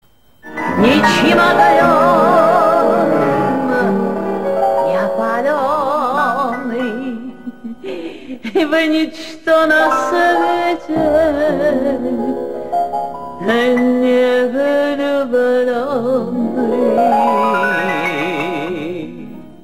Русский романс (300)